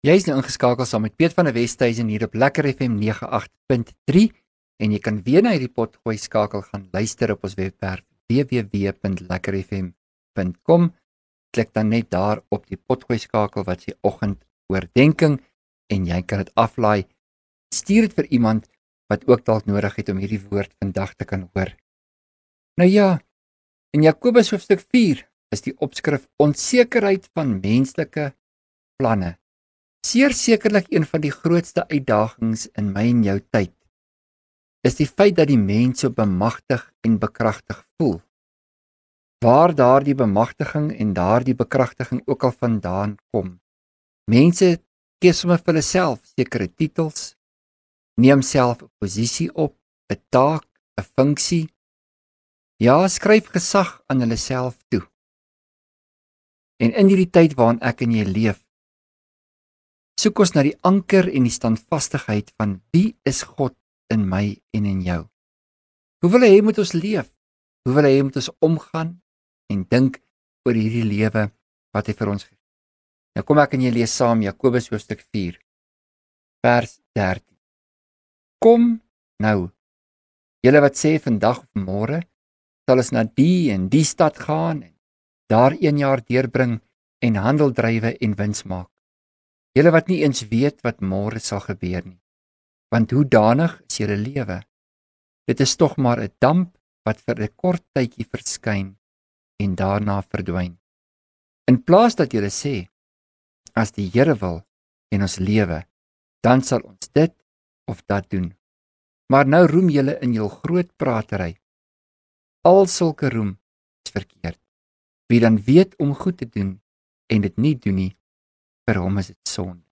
LEKKER FM | Oggendoordenkings